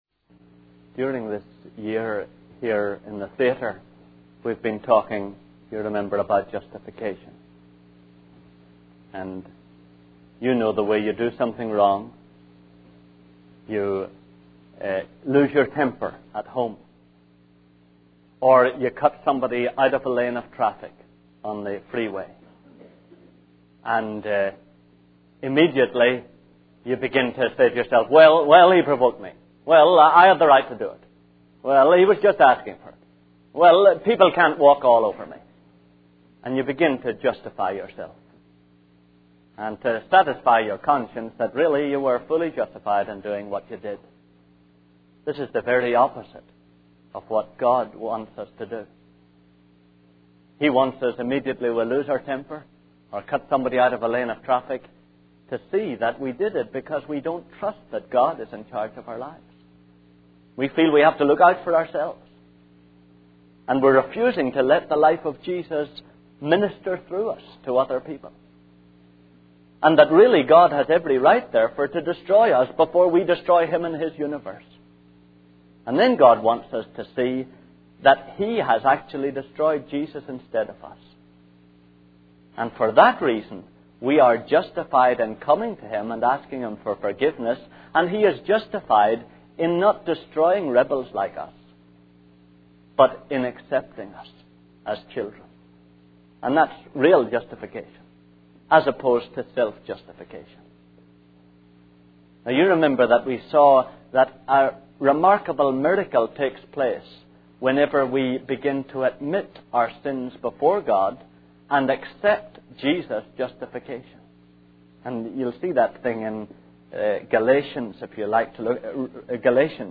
In this sermon, the speaker discusses the importance of surrendering our lives to God and allowing Him to direct our paths. They emphasize that we often change the ground of our justification from our belief in the blood of Jesus to the way things are working out in our lives.